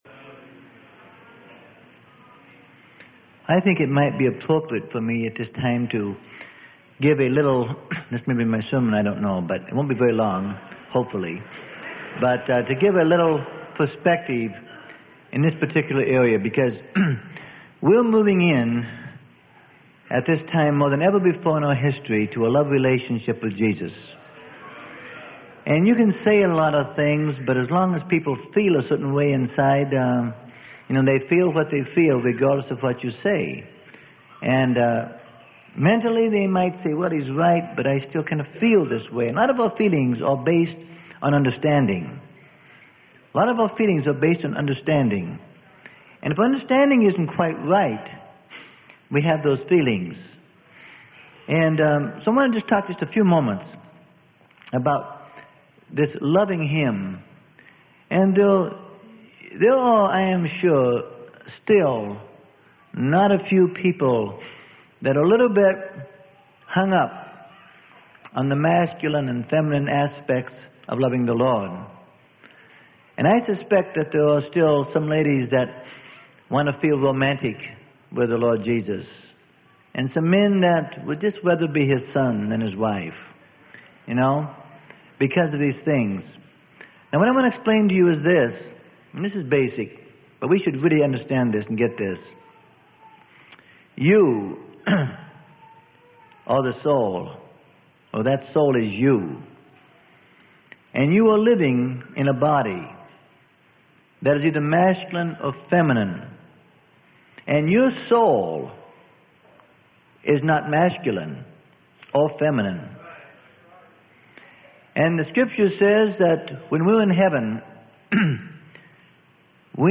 Sermon: The Path to Spiritual Security - Freely Given Online Library